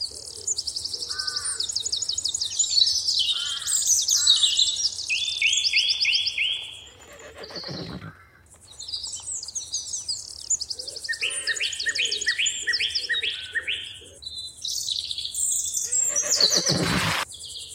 Ambiance Autour des écuries (Broadcast) – Le Studio JeeeP Prod
Bruits d’ambiance autour des écuries; : oiseaux, chevaux qui galopent, hennissent, …
Ecuries20.mp3